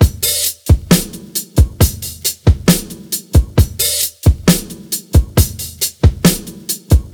• 67 Bpm Drum Loop D# Key.wav
Free breakbeat - kick tuned to the D# note. Loudest frequency: 3063Hz
67-bpm-drum-loop-d-sharp-key-Pz2.wav